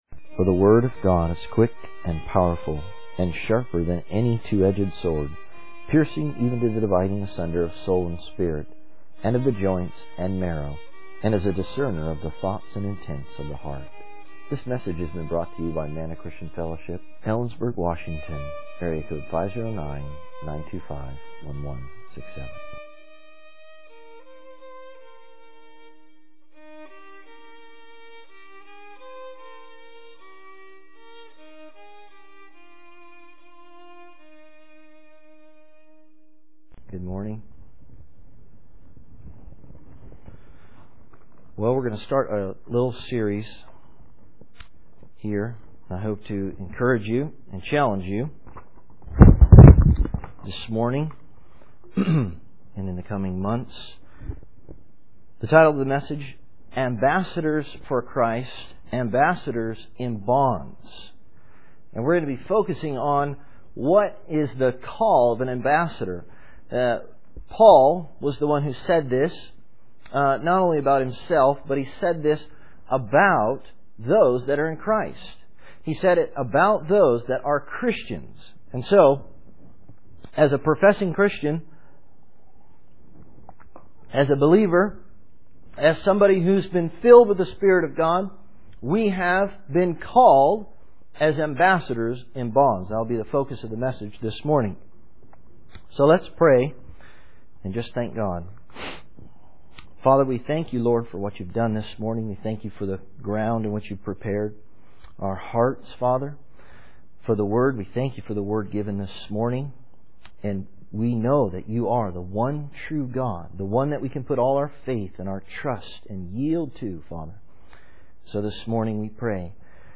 In this sermon, the speaker emphasizes the importance of being an ambassador for Jesus Christ. He highlights that ambassadors have a specific mission and message to deliver, and they are under God's authority.